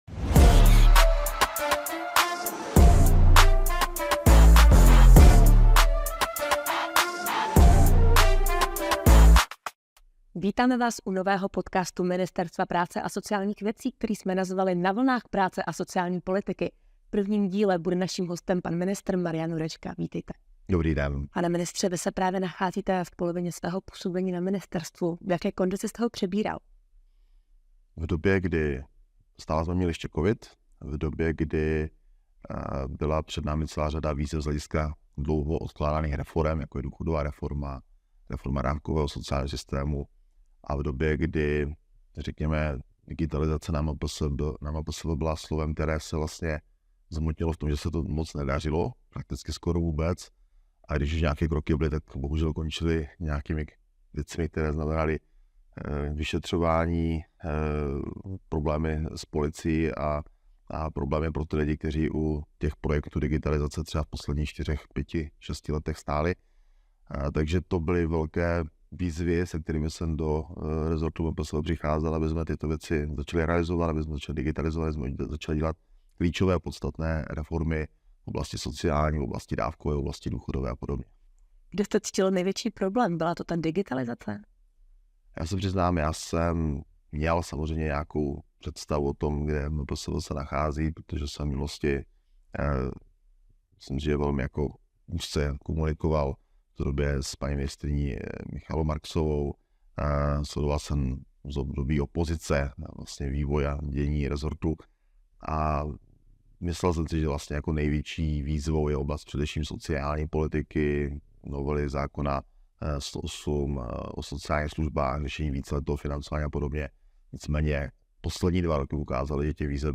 Formou rozhovorů s odborníky v něm bude přibližovat novinky, legislativní změny a jejich aplikaci do běžného provozu, ale také vysvětlovat novely zákonů a informovat o dění na ministerstvu.
První díl s ministrem Marianem Jurečkou je již dostupný k poslechu. Ministr v něm shrnuje své dosavadní působení ve funkci.